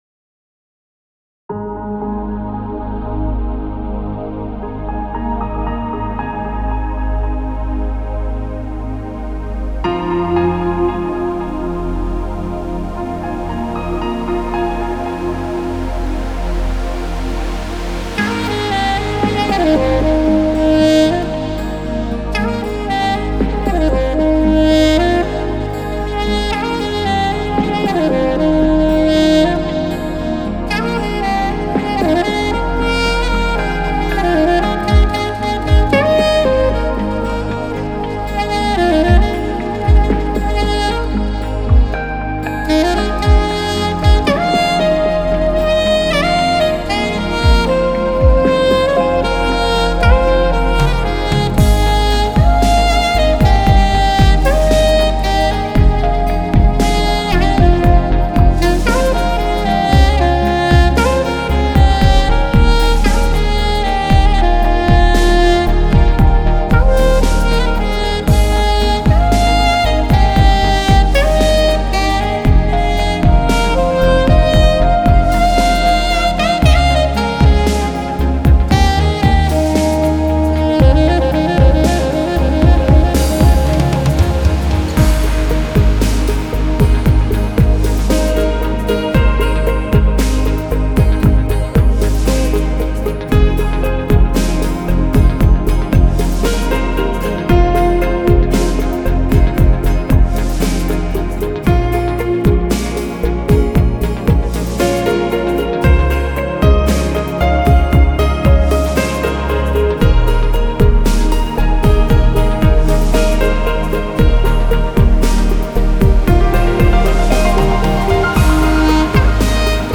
کیبورد
ساکسیفون
گیتار